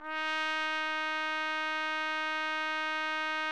TRUMPET   10.wav